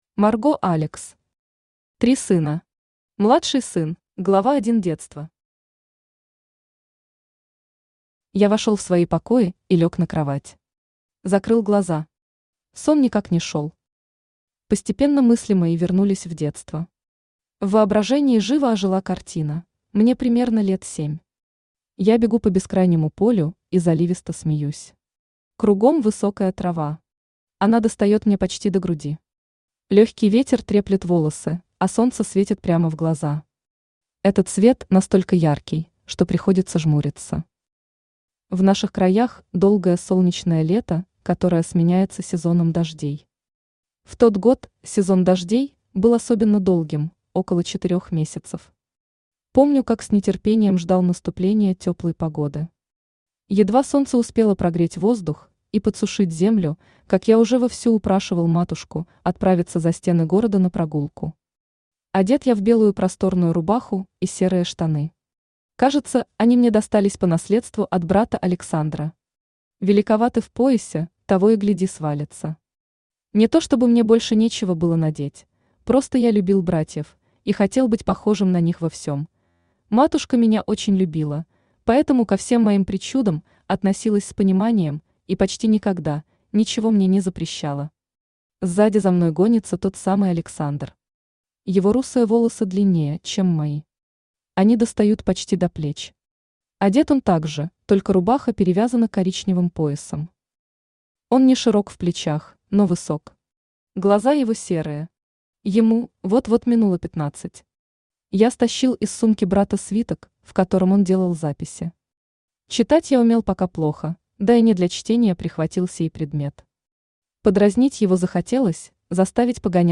Аудиокнига Три сына. Младший сын | Библиотека аудиокниг
Младший сын Автор Марго Алекс Читает аудиокнигу Авточтец ЛитРес.